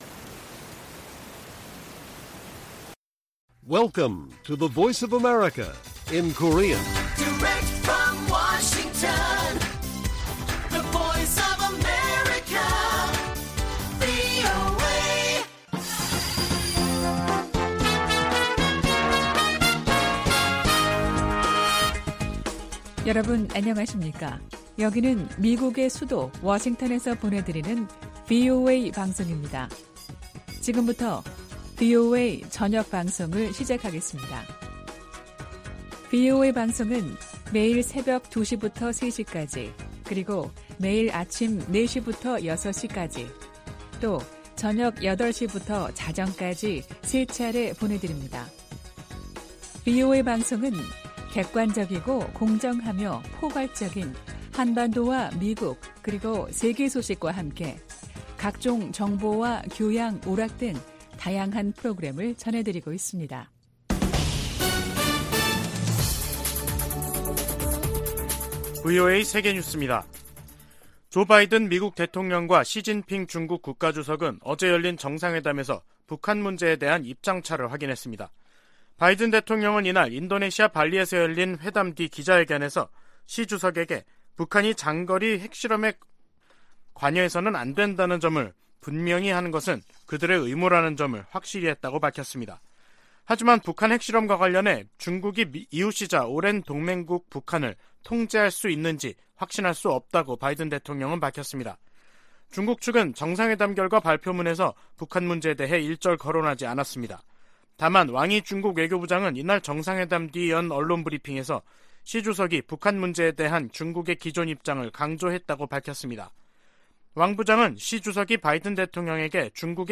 VOA 한국어 간판 뉴스 프로그램 '뉴스 투데이', 2022년 11월 15일 1부 방송입니다. 조 바이든 미국 대통령은 인도네시아에서 열린 미중 정상회담에서 시진핑 국가주석에게 북한이 핵실험 등에 나서면 추가 방위 조치를 취할 것이라고 말했다고 밝혔습니다. 미국과 중국의 정상회담에서 북한 문제 해법에 대한 견해차가 확인되면서 한반도를 둘러싸고 높아진 긴장이 지속될 전망입니다.